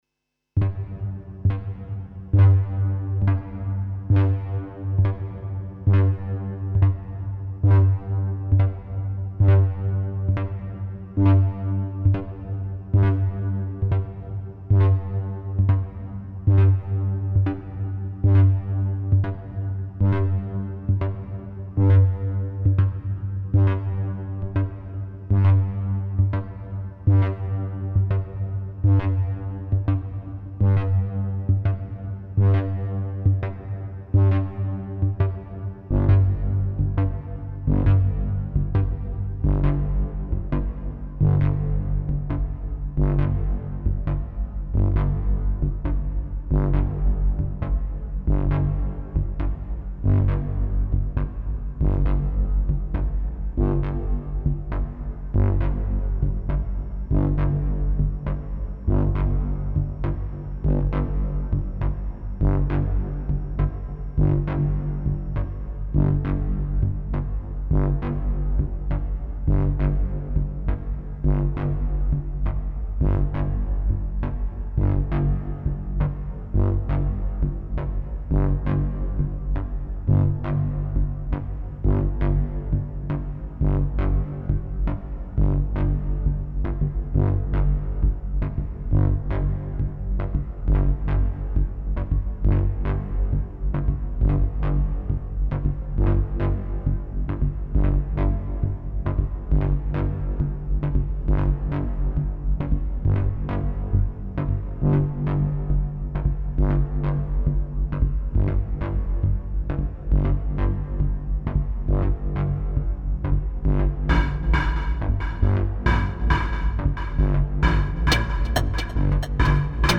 This is a piece of music with two sequencers being slightly out of sync (and with independent master clocks).
Instruments used: Doepfer A-100.
Clock frequencies have been manually adjusted and will drift during the piece.
Delays are mixed to the opposite pan position (left voce’s delay will appear on the right side) via A-138m.
As soon as I do this, there’s some additional percussion: it is triggered only when the Quantizers (used for the sequencers) begin to detect different notes.
Finally, we hear some pad-alike sounds (again separate voices for left/right).